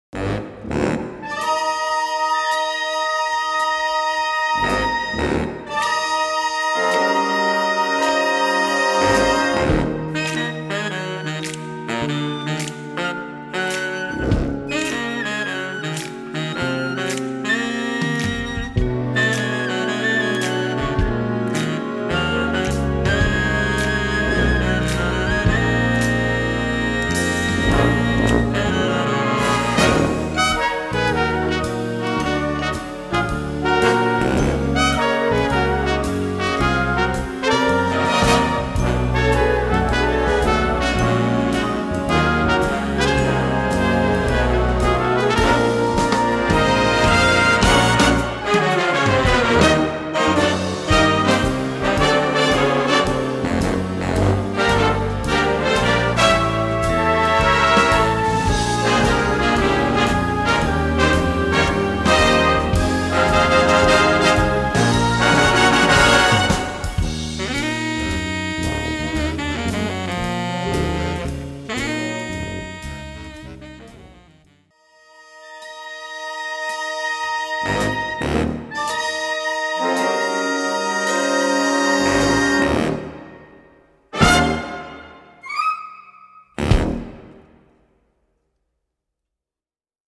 Gattung: Cool Swing
Besetzung: Blasorchester
sanfte und swingende, aber dennoch sehr COOLE